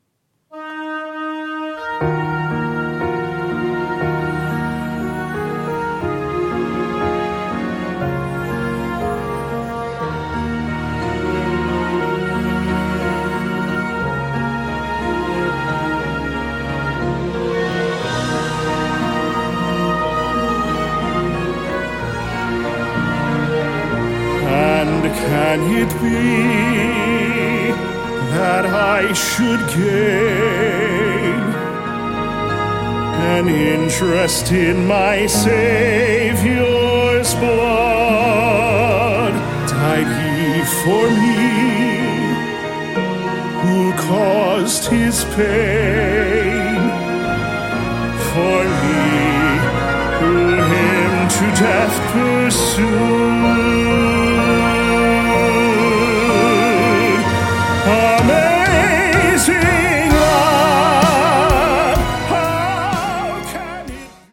Opera & Classical Crossover